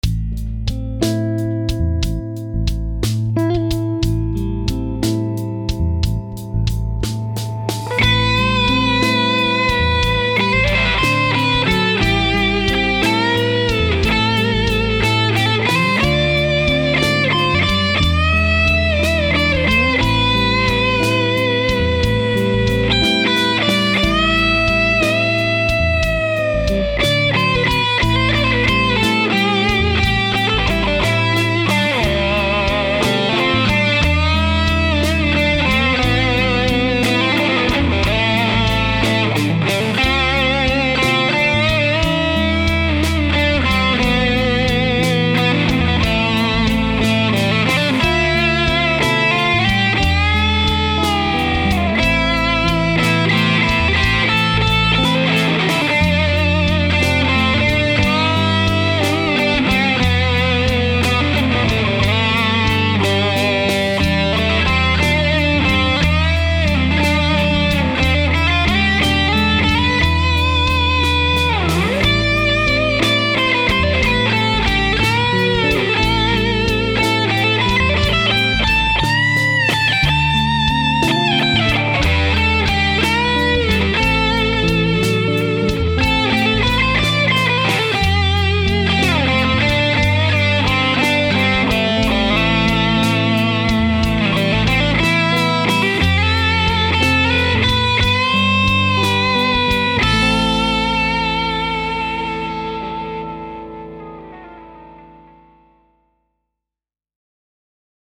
Fun With Re-Amping
Re-amping through the Hot Rod allowed me to take advantage of its reverb, but with two amps going at the same time, it totally fattened up my sound without making it murky.
reamp-test.mp3